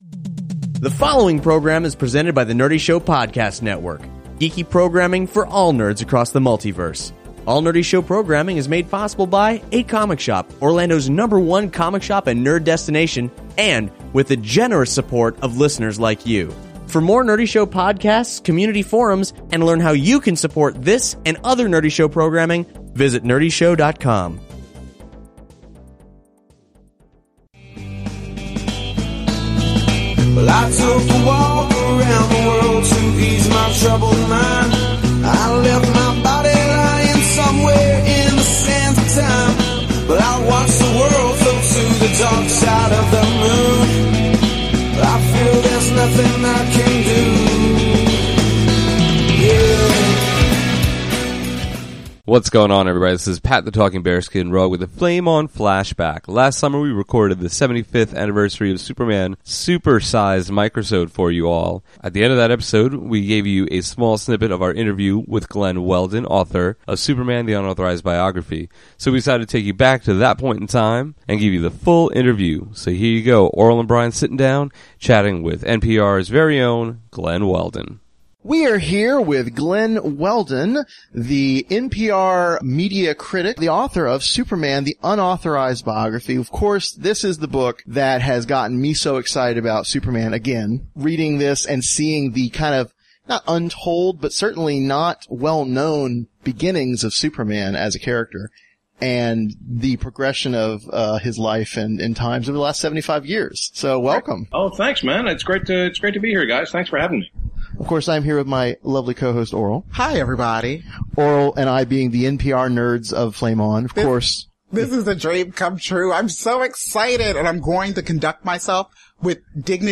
Episode 55 :: Glen Weldon Interview